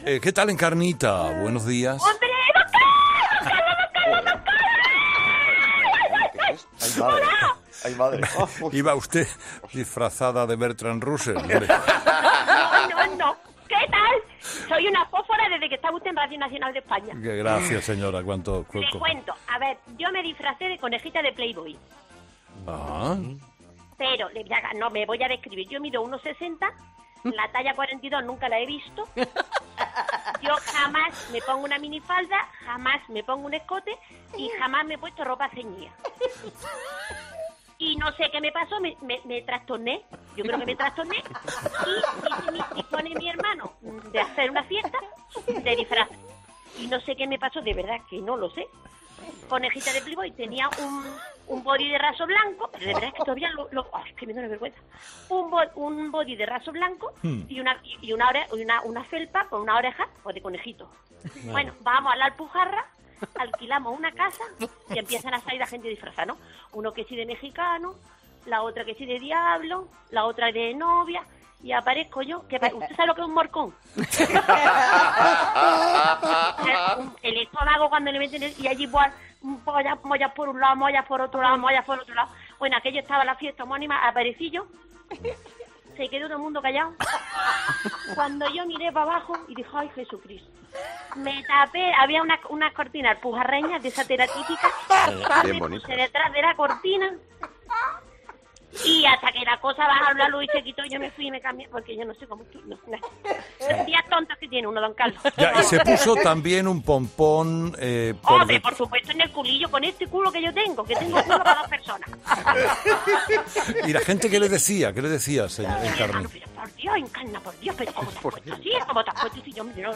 Una 'fósfora' se desgañita de la emoción al saludar a Herrera y le desvela su disfraz más picarón